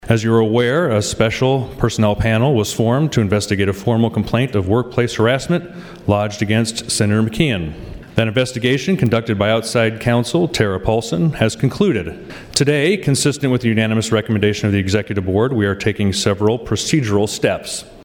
SENATOR HANSEN SPOKE AGAINST MCKEON BEFORE THE FULL LEGISLATURE WEDNESDAY: